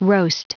Prononciation du mot roast en anglais (fichier audio)
Prononciation du mot : roast